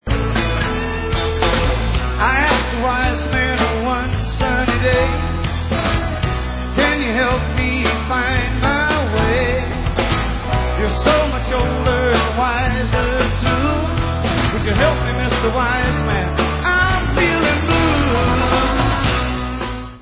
Live At Fox Theatre
sledovat novinky v oddělení Southern (jižanský) rock